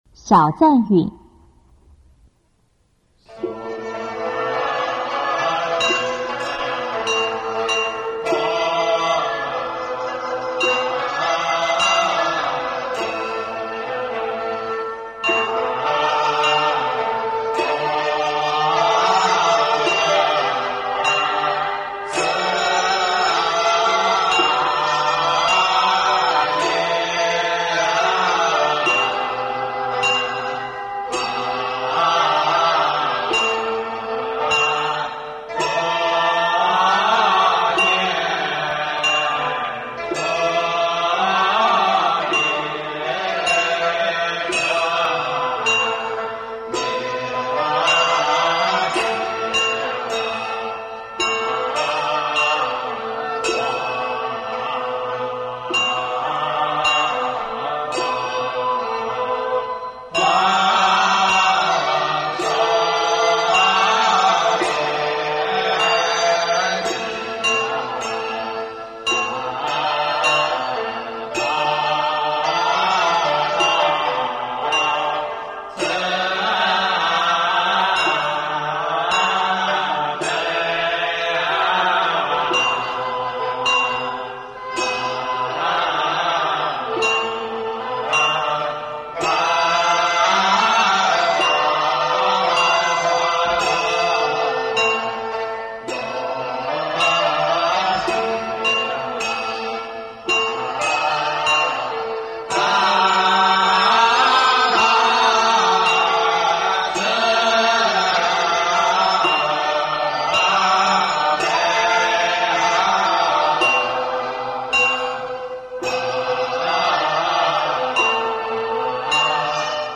中国道教音乐 全真正韵 小赞韵
简介：阴阳法事都普遍运用，凡斋醮科仪将毕就用这首，表示诵经功德，以示诚敬，对不同的神可使用不同的赞词。